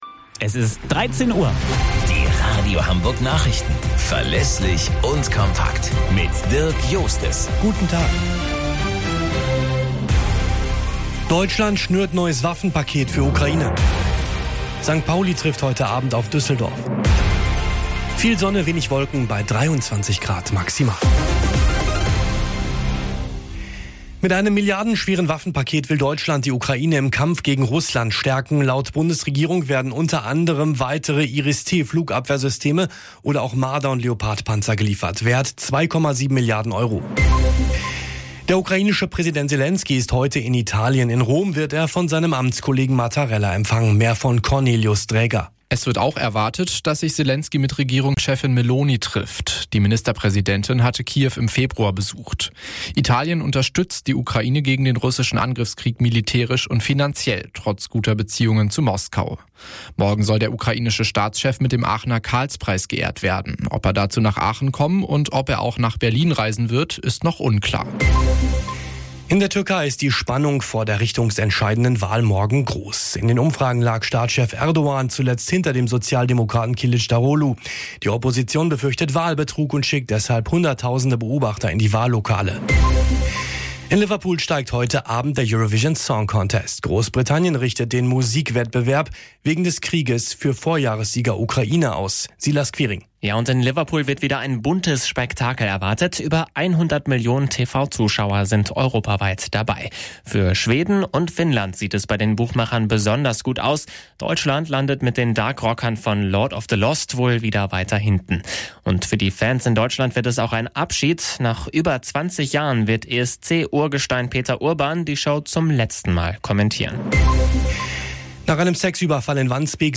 Radio Hamburg Nachrichten vom 13.05.2023 um 16 Uhr - 13.05.2023